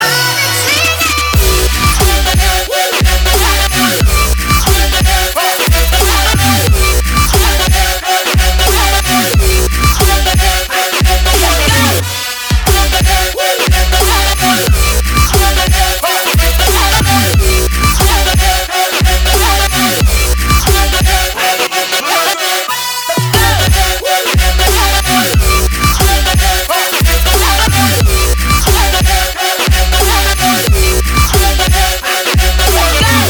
громкие
EDM
Trap
быстрые
Bass
Стиль: trap